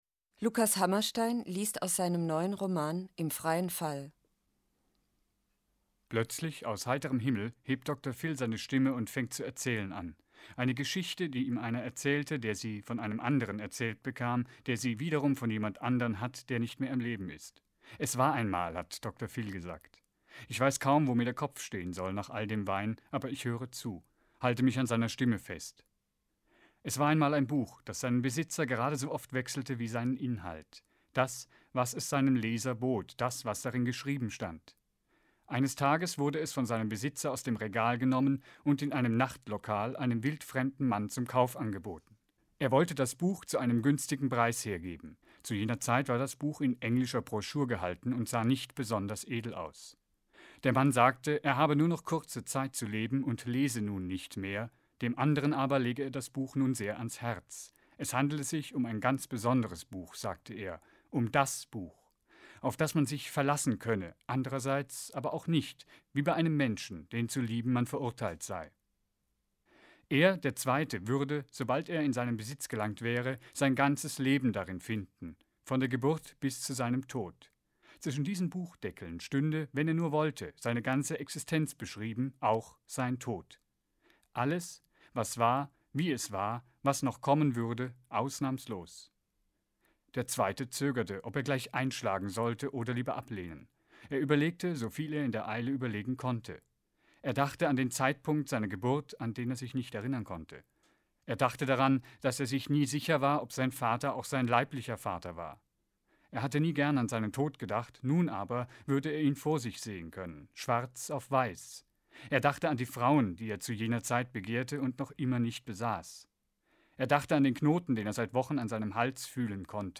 Das Literaturtelefon-Archiv wird in der Monacensia im Hildebrandhaus aufbewahrt.
Die Monacensia und das Literaturportal Bayern präsentieren monatlich eine Auswahl dieser Lesungen.